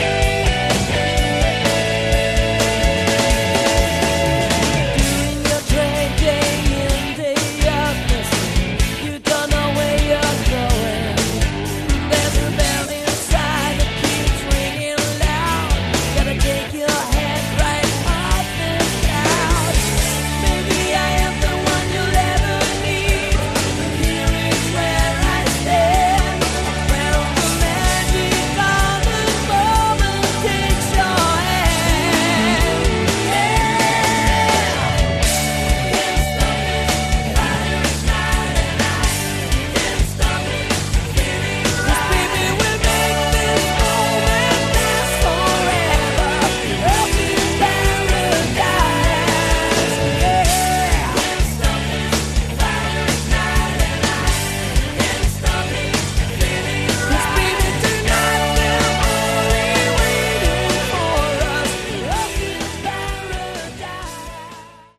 Category: Melodic Hard Rock/AOR